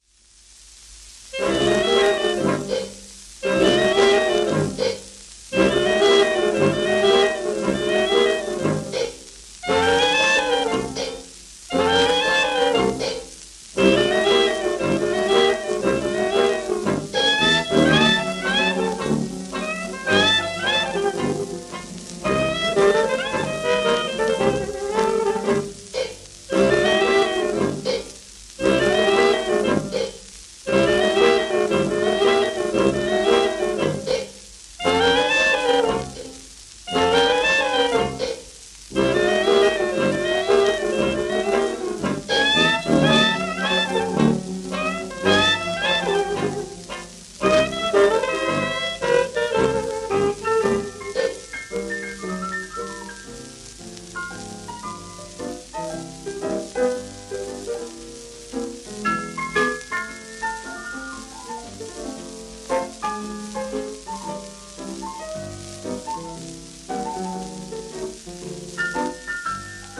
1928年頃の録音